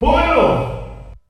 The announcer saying Jigglypuff's name in German releases of Super Smash Bros.
Jigglypuff_German_Announcer_SSB.wav